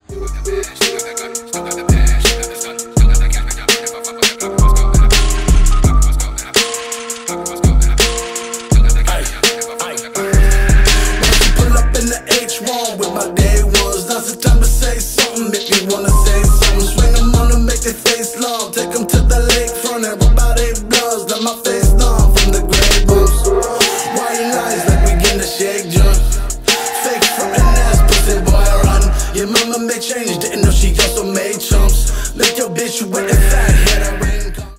gangsta rap
хип-хоп